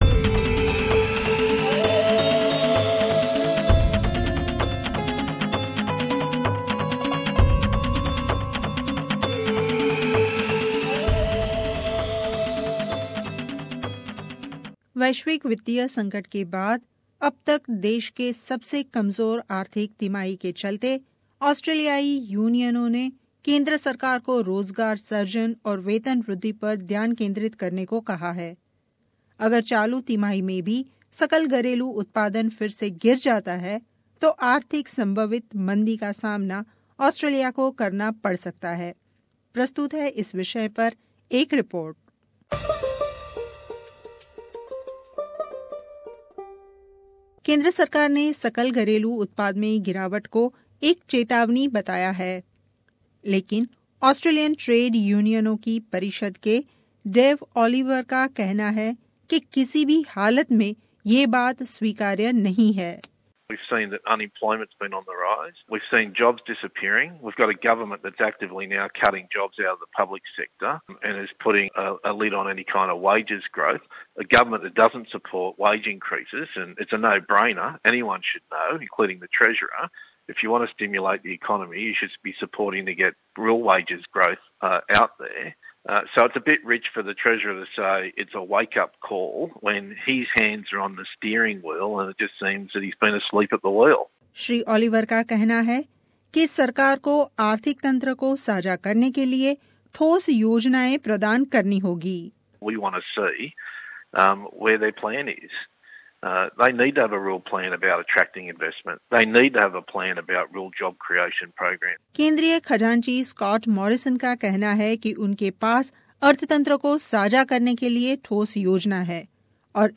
वैश्विक आर्थिक मंदी के बाद पहेली बार ऑस्ट्रेलियाई अर्थतंत्र का प्रदर्शन पिछली तिमाही में कमजोर रहा, अगर इस तिमाही में भी अगर कुछ सुधार न किया गया तो ऑस्ट्रेलिया वापस मंदी की मार झेल सकता है. प्रस्तुत है रिपोर्ट